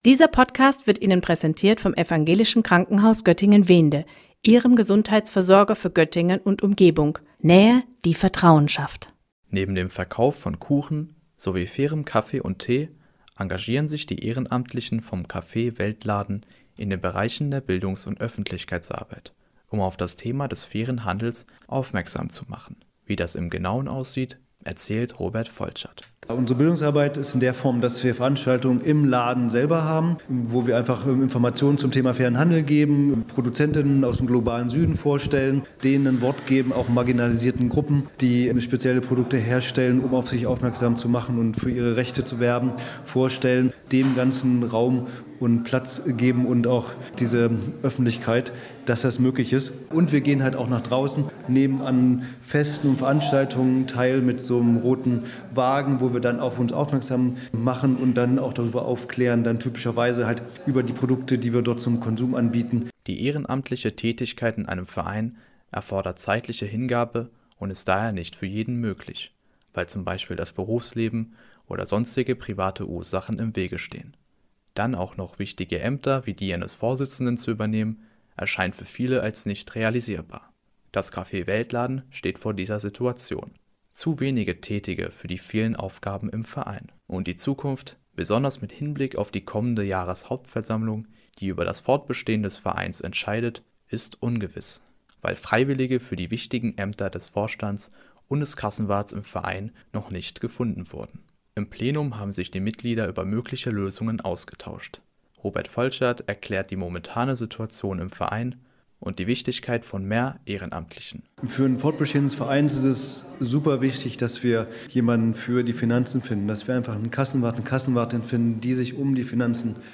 Es droht jedoch die Schließung, weil zu wenige Ehrenamtliche tätig sind, die auch wichtige Ämter innerhalb des Vereins übernehmen. Anlässlich dessen haben sich die Mitglieder des Vereins in den Räumlichkeiten der katholischen Hochschulgemeinde zusammengefunden, um über die Zukunft des Vereins zu diskutieren.